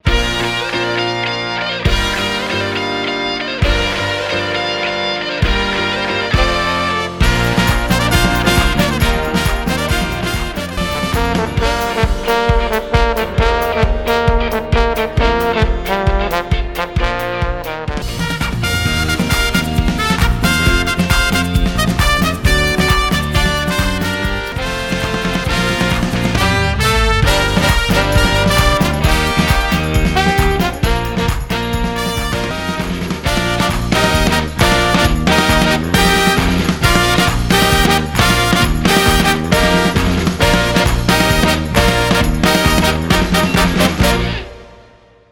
難易度 分類 並足134 時間 3分44秒
編成内容 大太鼓、中太鼓、小太鼓、シンバル、トリオ 作成No 313